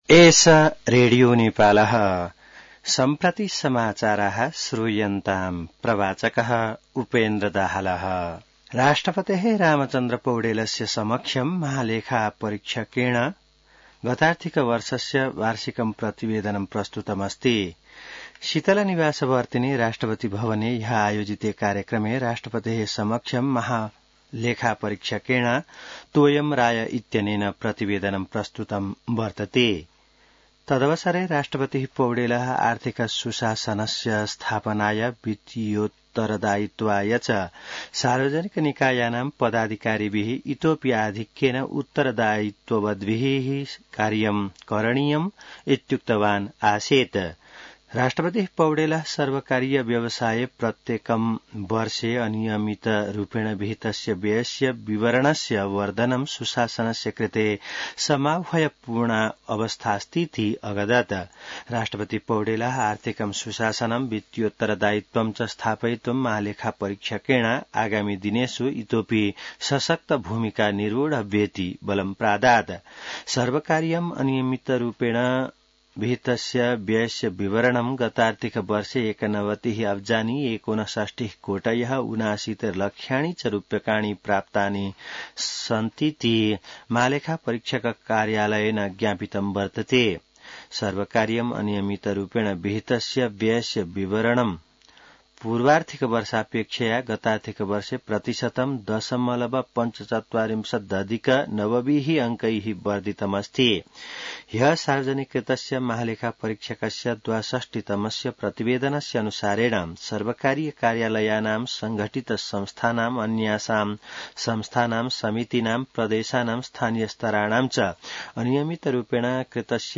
संस्कृत समाचार : १ जेठ , २०८२